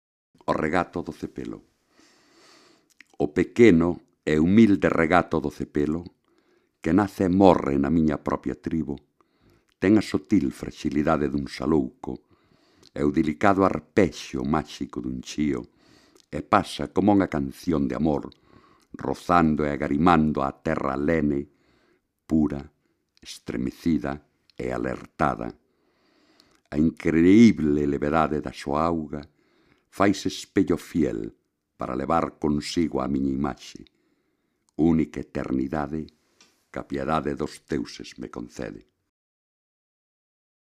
Autoría: Manuel María   Intérprete/s: Manuel María